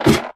step / ladder5